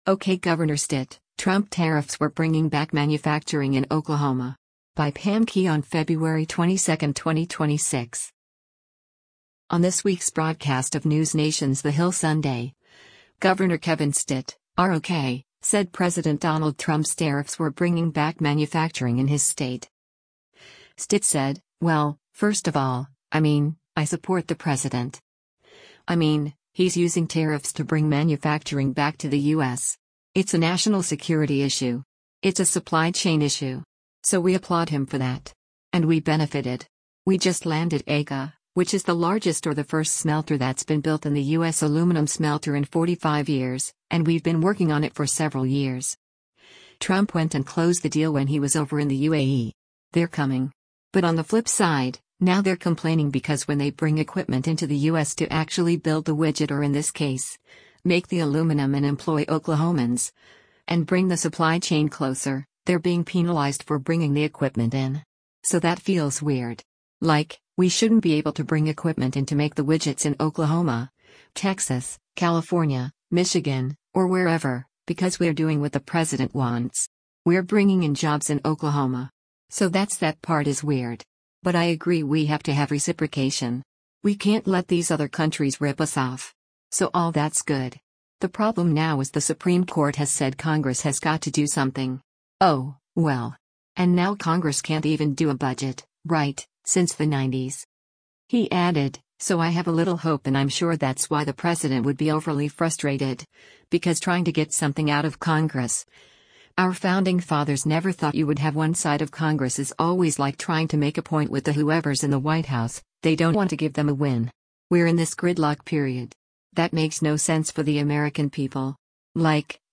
On this week’s broadcast of NewsNation’s “The Hill Sunday,” Gov. Kevin Stitt (R-OK) said President Donald Trump’s tariffs were bringing back manufacturing in his state.